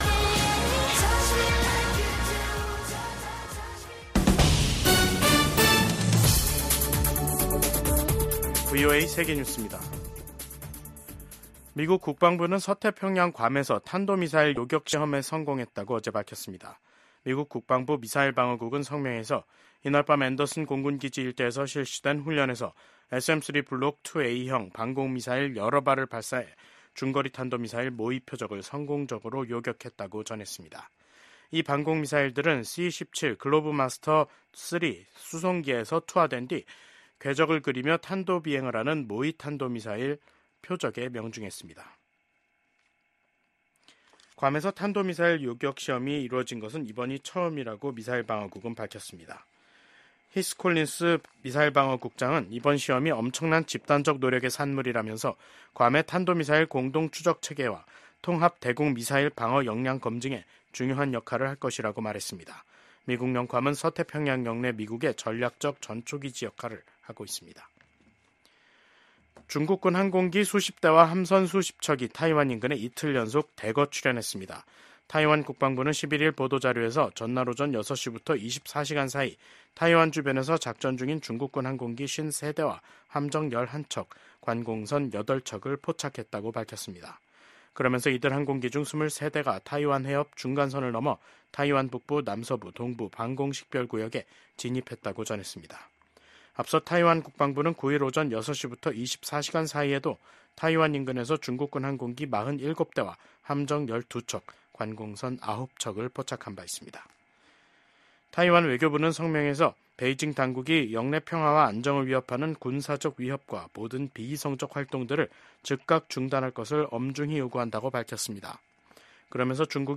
VOA 한국어 간판 뉴스 프로그램 '뉴스 투데이', 2024년 12월 11일 3부 방송입니다. 12.3 비상계엄 사태를 수사하고 있는 한국 사법당국은 사건을 주도한 혐의를 받고 있는 김용현 전 국방부 장관을 구속했습니다. 한국의 비상계엄 사태 이후 한국 민주주의가 더욱 강해졌다고 미국 인권 전문가들이 평가했습니다.